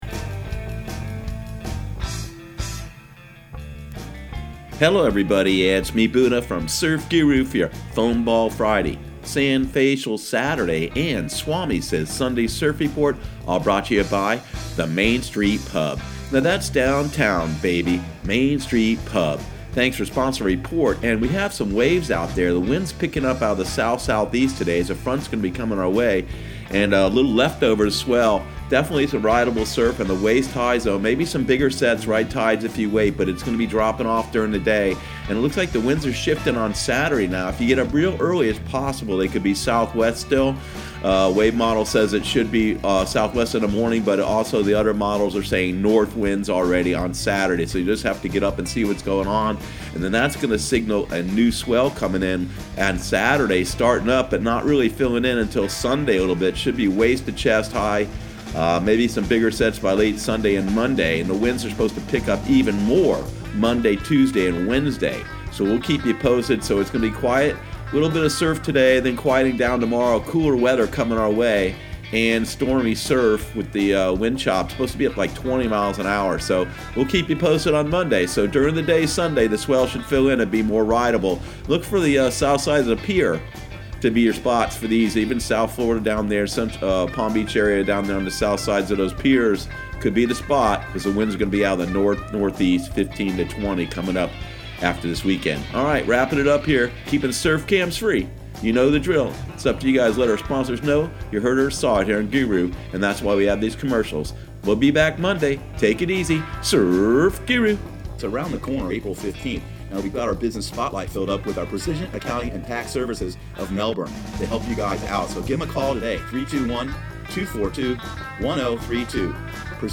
Surf Guru Surf Report and Forecast 03/15/2019 Audio surf report and surf forecast on March 15 for Central Florida and the Southeast.